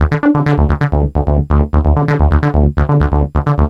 SONS ET LOOPS GRATUITS DE BASSES DANCE MUSIC 130bpm
Basse dance 3 C